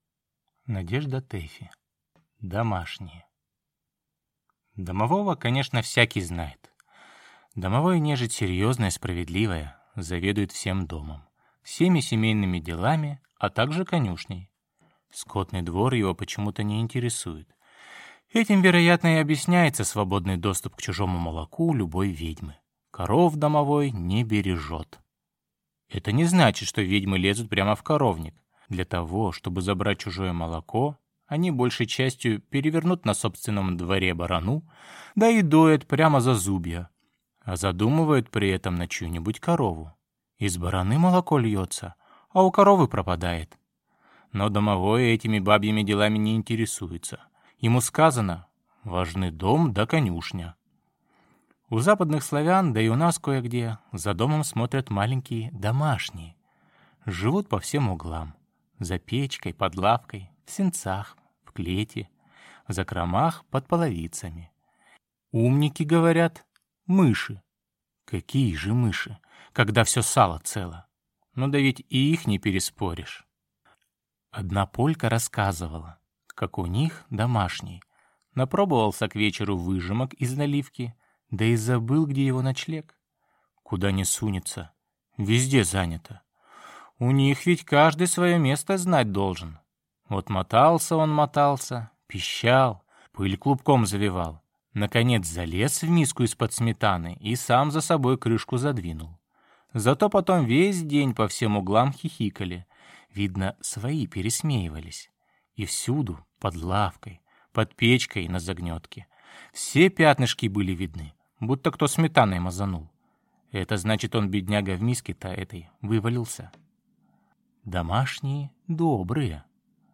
Аудиокнига «Домашние» | Библиотека аудиокниг